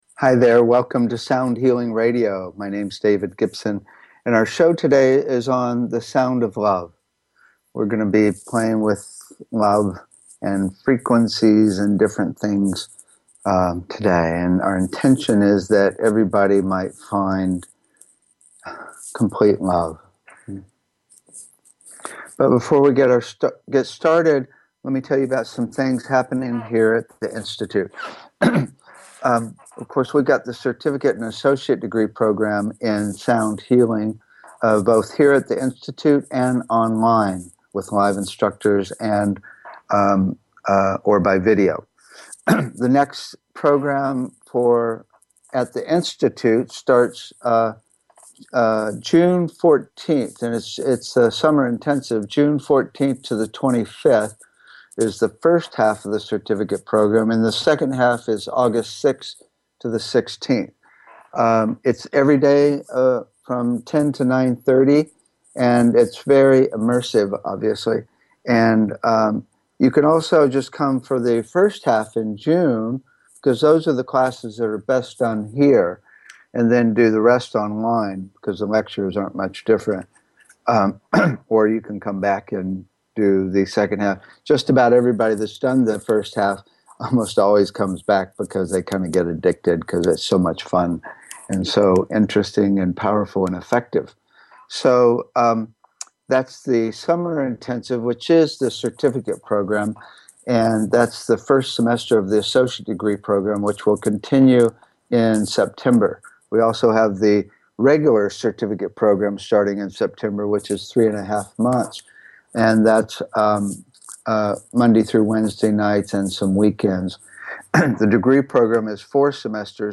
The show is a sound combination of discussion and experience including the following topics: Toning, Chanting and Overtone Singing - Root Frequency Entrainment - Sound to Improve Learning -Disabilities - Using Sound to Connect to Spirit - Tuning Fork Treatments - Voice Analysis Technologies - Chakra Balancing - Sound to Induce Desired States of Being - Tibetan/Crystal Bowl Massage - Electronic Nerve Stimulation - Water Sound Infusion Systems - Sound Visualization systems - Infratonics Holographic Sound - Scalar wave EESystem Drumming and Rhythm - HydroAcoustic Therapy - Neurophone Bio-Tuning - Sound Surgery - Cymatics Frequency based therapeutic devices - VibroAcoustic Sound Chairs and Tables /soundhealing#archives /soundhealing#showposts /soundhealing#upcoming /customshow/2574 /customshow/mrss/2574 /soundhealing#feedback BBS Station 1 Bi-Weekly Show -e- 7:00 pm CT 7:55 pm CT Saturday Education Energy Healing Sound Healing Love & Relationships Emotional Health and Freedom Mental Health Science Self Help Spiritual 0 Following Login to follow this talk show Sound Healing